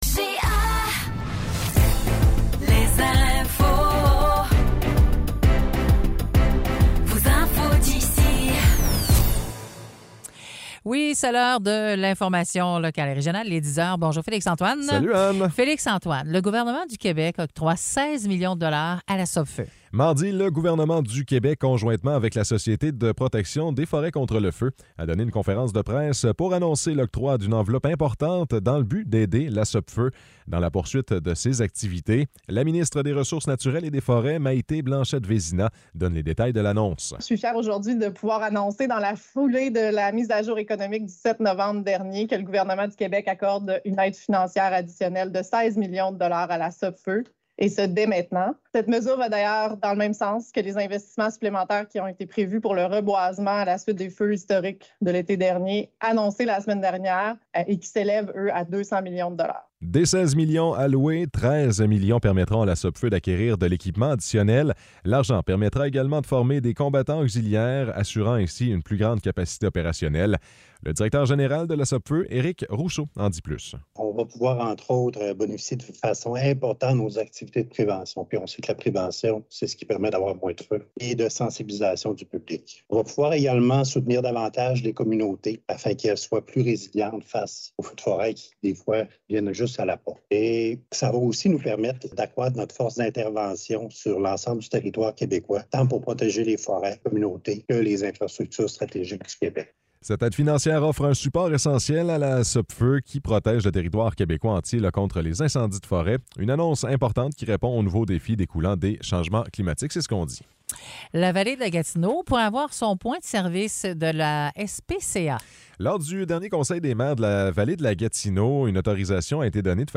Nouvelles locales - 15 novembre 2023 - 10 h